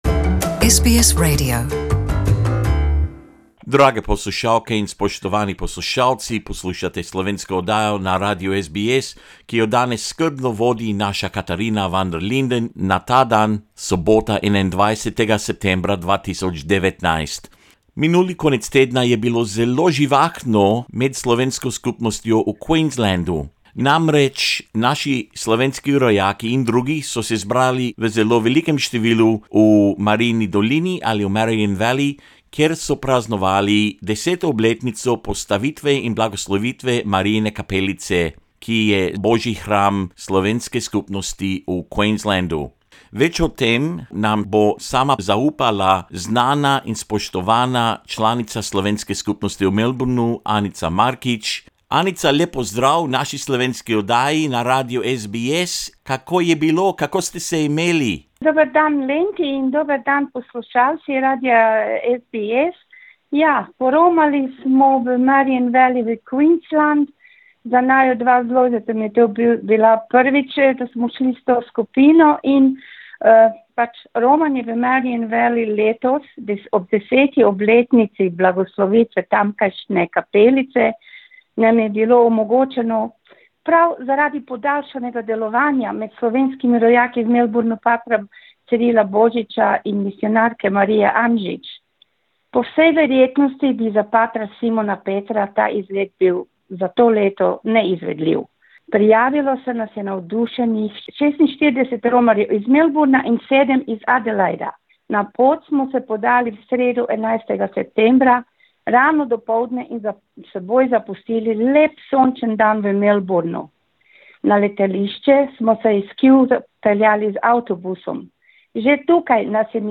nam poroča o tem posebnem dogodku.